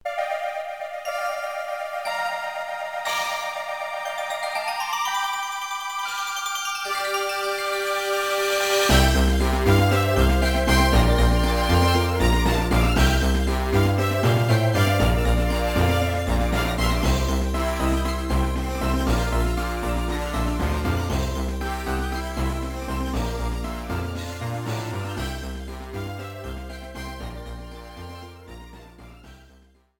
contribs)Proper fadeout.